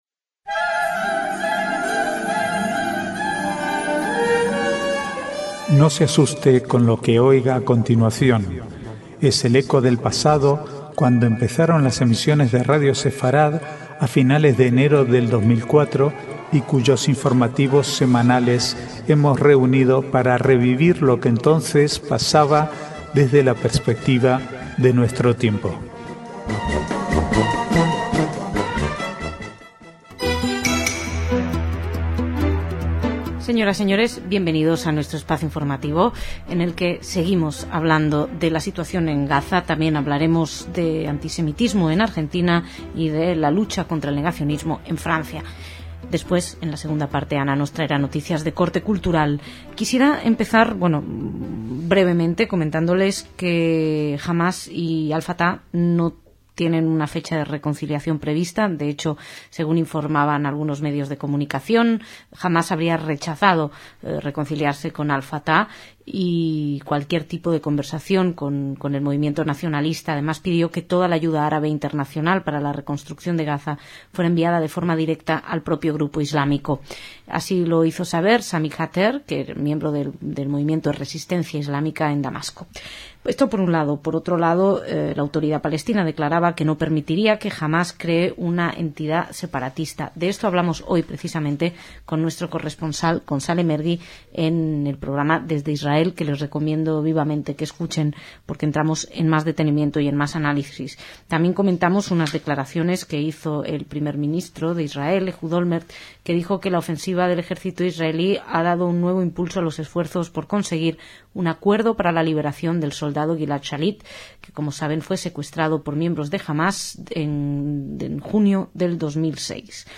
Archivo de noticias del 23 al 28/1/2009